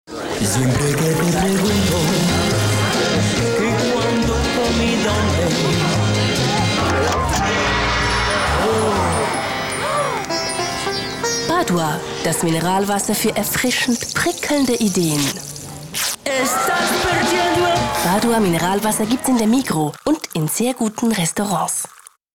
Werbung Hochdeutsch (CH)
Sprecherin mit breitem Einsatzspektrum.